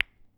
BallCollision.wav